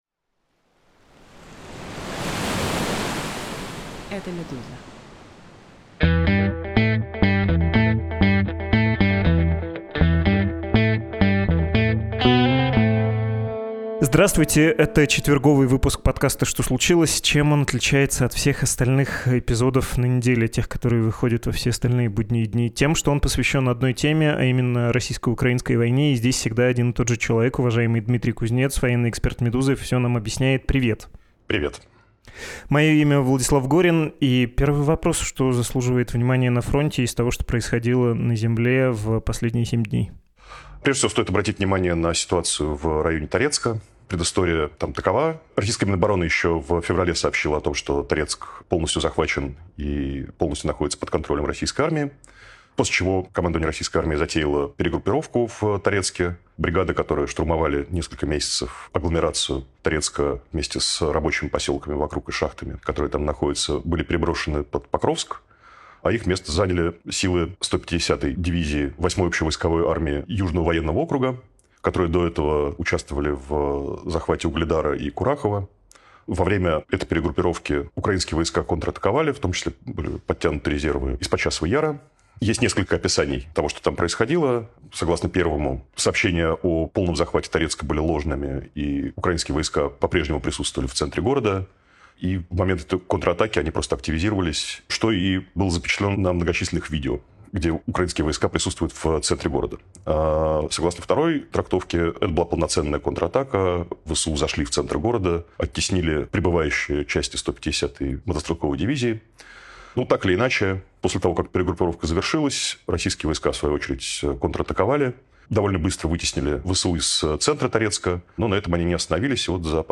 Исследователь Владимир Гельман написал книгу о том, почему в России не приживается демократия. Мы с ним поговорили.